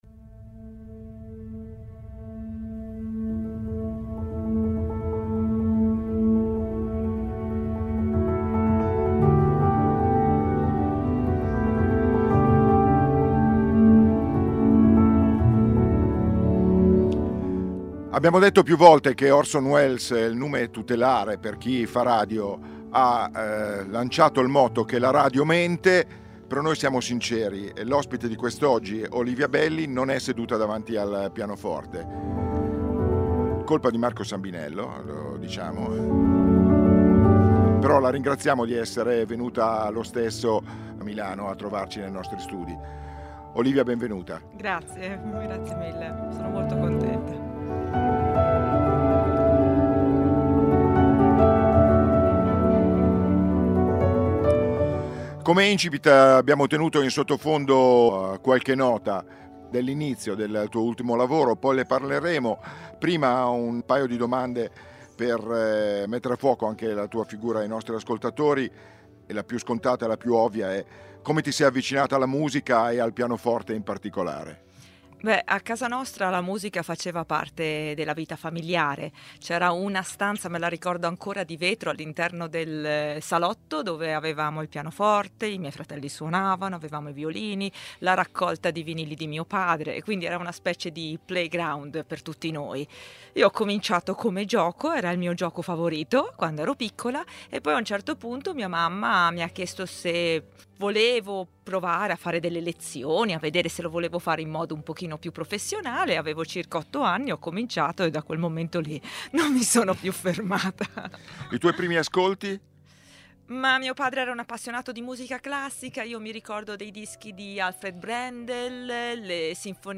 Il nuovo lavoro, i tour in giro per il mondo, la scelta di vivere in campagna, l'impegno a favore dell'ambiente, i suoi sogni, il film dove vorrebbe abitare... Ascolta l'intervista